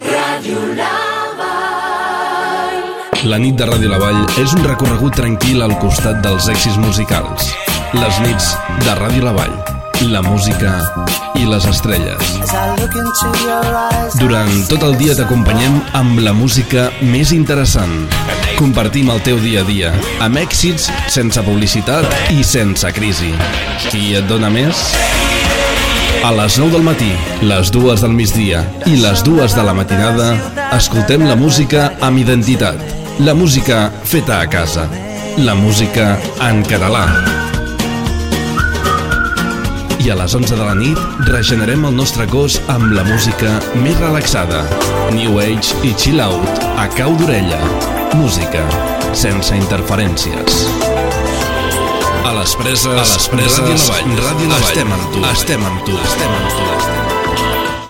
Data emissió 2018 Banda FM Localitat Preses, les Comarca Garrotxa Durada enregistrament 00:57 Idioma Català Notes Enregistrament extret del programa "Les Veus dels Pobles" de Ràdio Arrels.